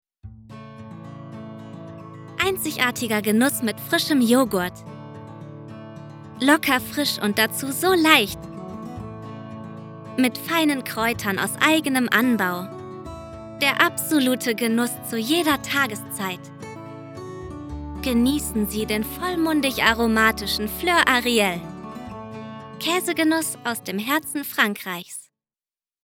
Sprecherin mit jugendlicher Stimme & Schauspielausbildung
Kein Dialekt
Sprechprobe: Werbung (Muttersprache):
Talent with young voice and acting education
Werbung_2020_Käse.mp3